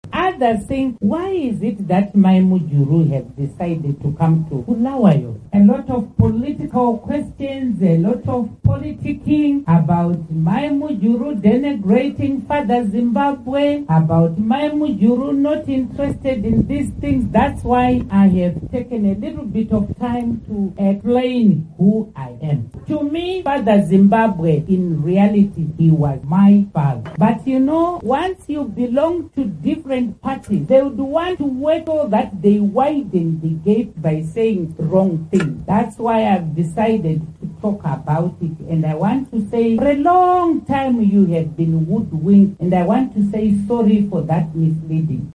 Joyce Mujuru Speaking Stanely Square in Bulawayo
Former Vice President Joice Mujuru says she is ready to work with opposition parties in Zimbabwe. She spoke at her maiden rally in Matabeleland region at the weekend.